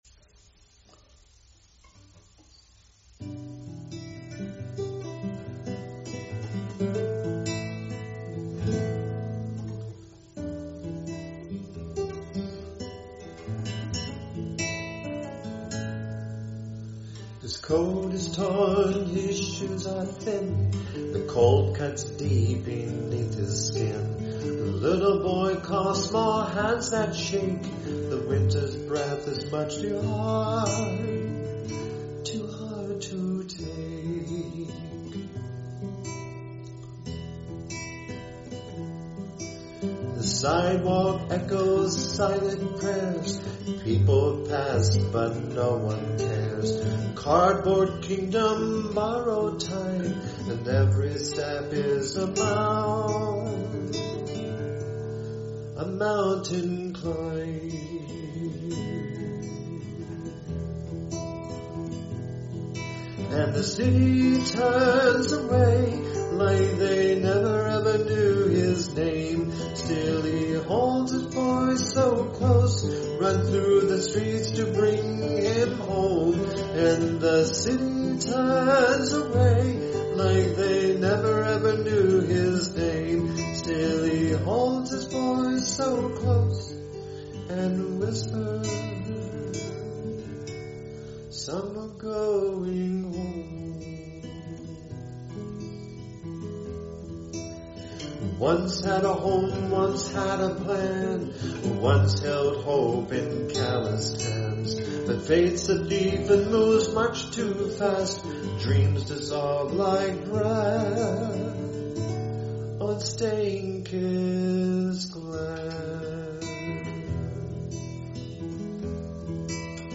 I wrote this as a song and thought I would give it a try to record it with my guitar and sing. I don't have professional equipment, so it might sound a bit raw and had to compress the bit rate, but I hope you can appreciate the sentiment behind it.